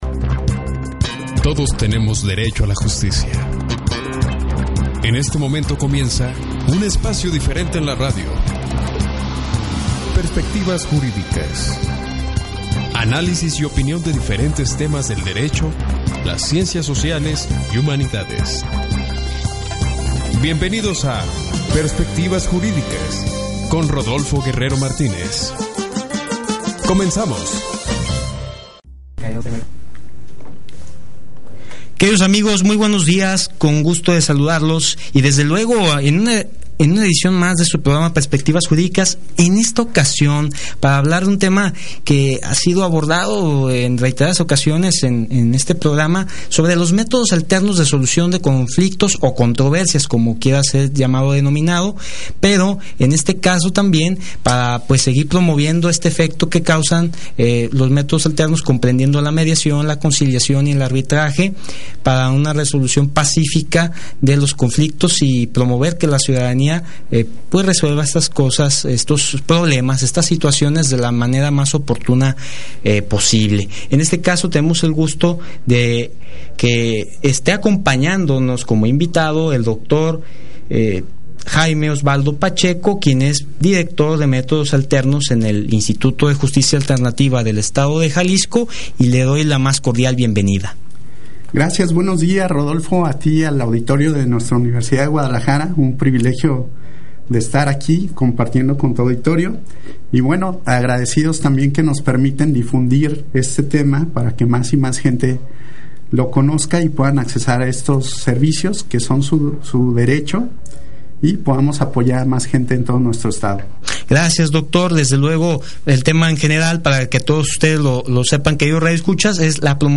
En entrevista para Perspectivas Jurídicas